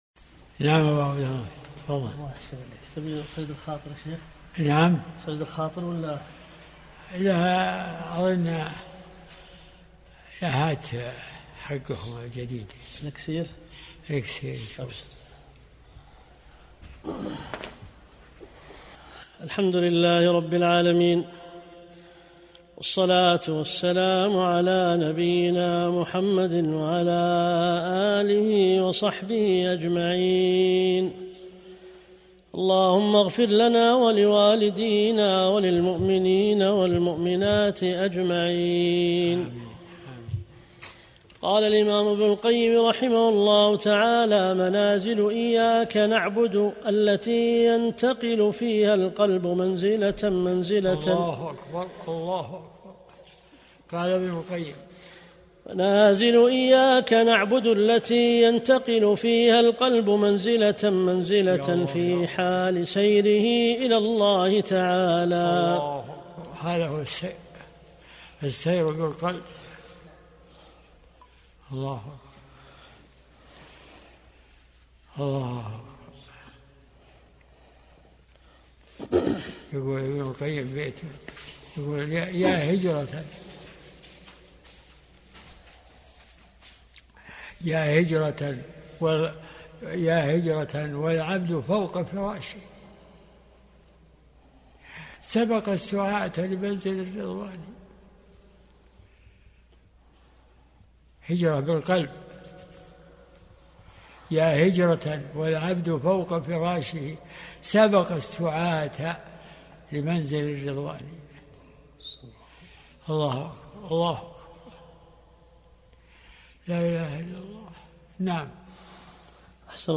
درس الأربعاء 72